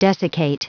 Prononciation du mot desiccate en anglais (fichier audio)
Prononciation du mot : desiccate